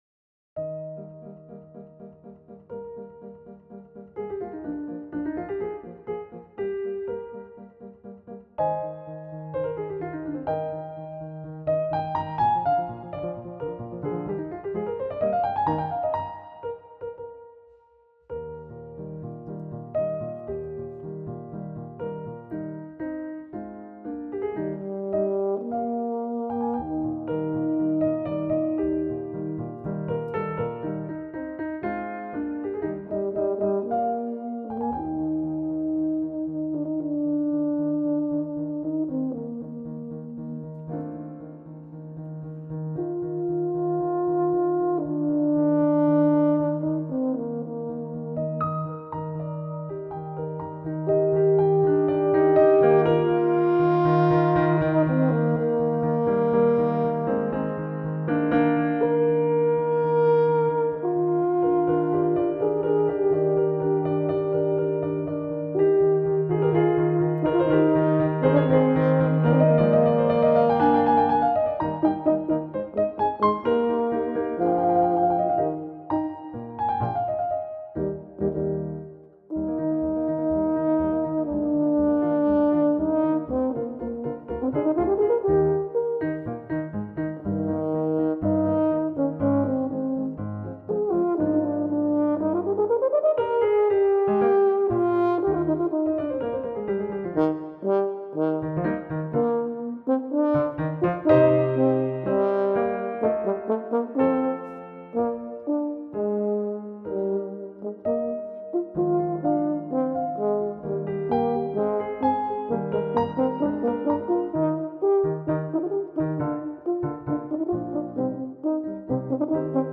Hornist
Pianist